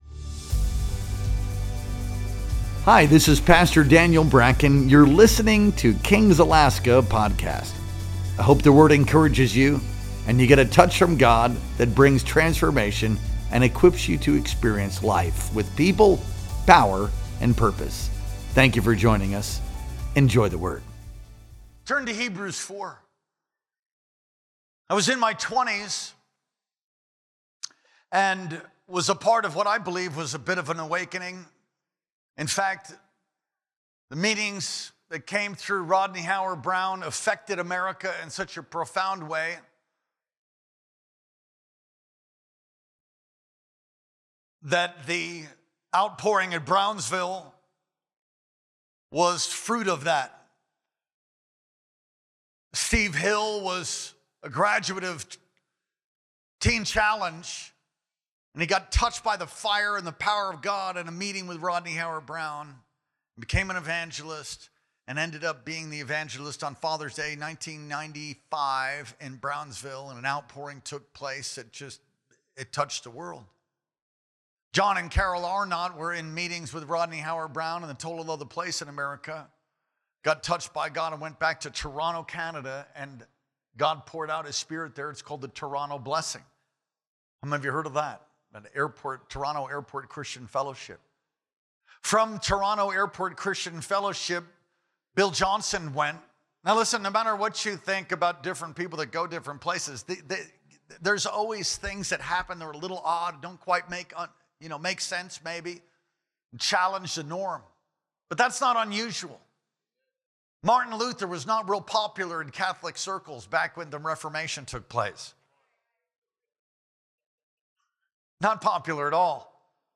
Our Sunday Night Worship Experience streamed live on February 26th, 2025.